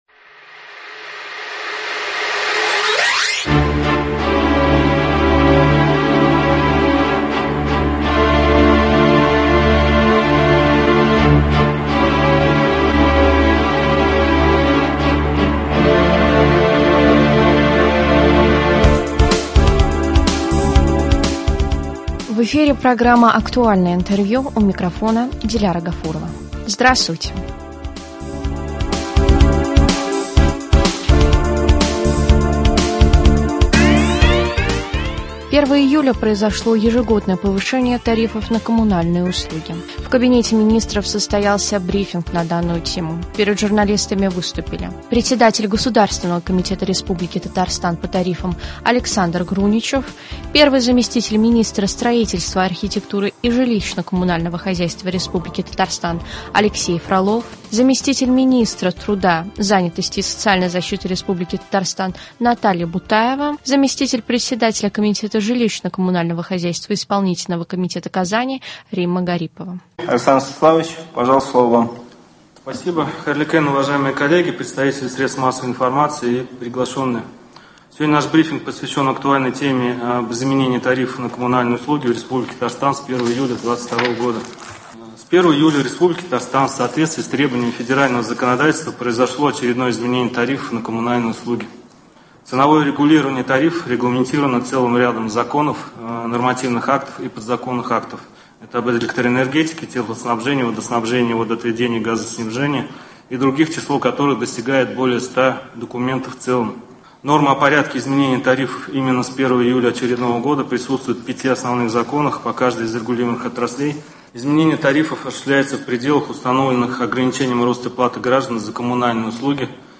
Актуальное интервью (06.07.22)
В Татарстане с 1 июля установлены новые тарифы за коммунальные услуги. О том, как изменились коммунальные платежи, журналистов проинформировал председатель Госкомитета РТ по тарифам Александр Груничев. Брифинг прошел в Доме Правительства РТ.